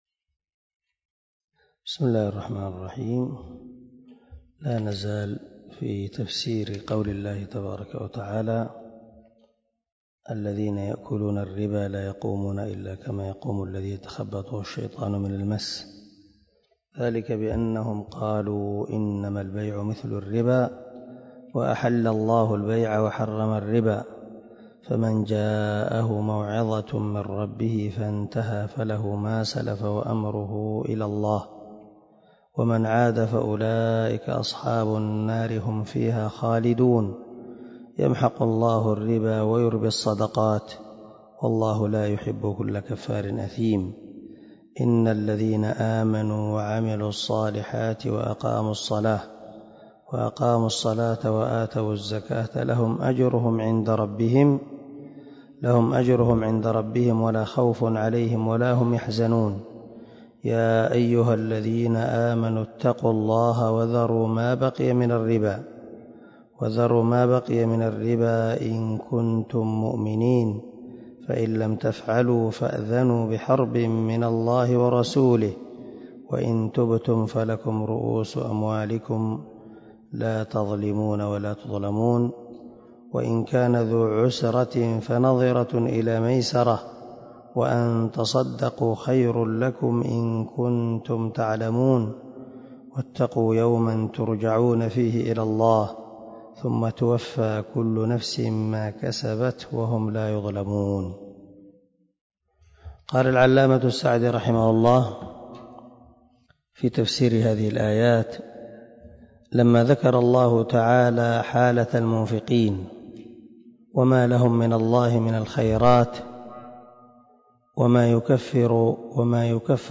149الدرس 139 تابع تفسير آية ( 275 – 281 ) من سورة البقرة من تفسير القران الكريم مع قراءة لتفسير السعدي